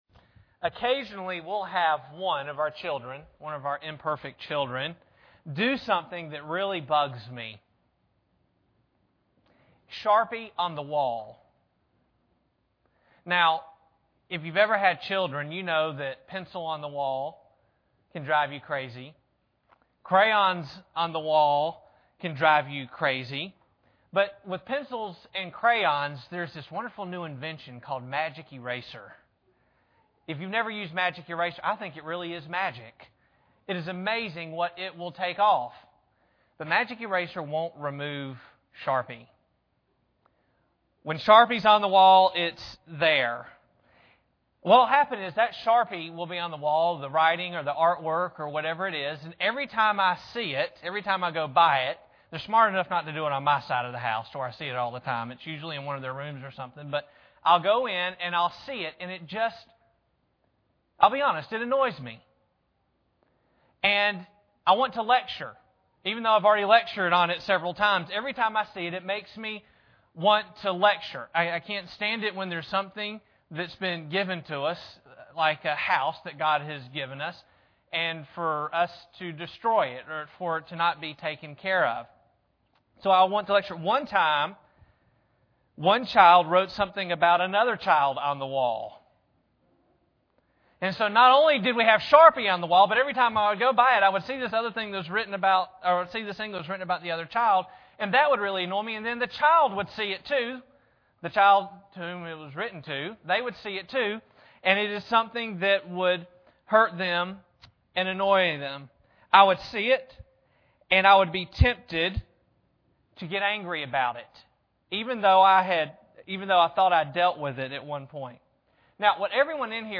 1 Peter 4:8 Service Type: Sunday Evening Bible Text